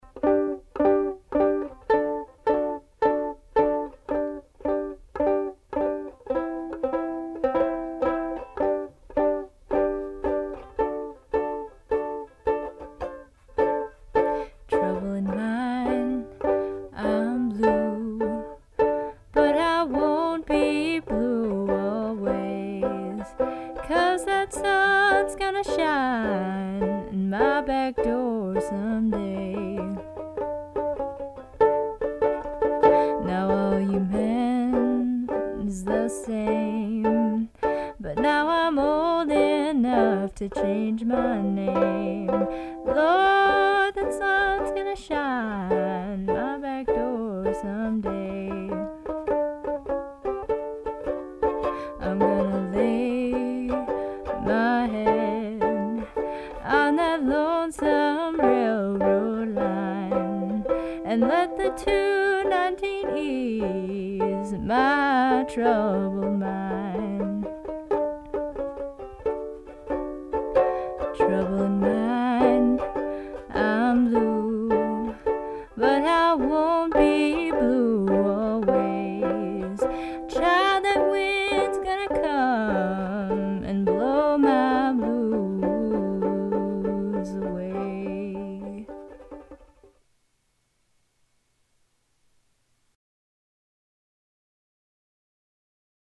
A track my lady and I did on the Tascam 244.
We used two figure 8 mics recorded at the same time. I'm pretty sure I did nothing but a high pass filter on this...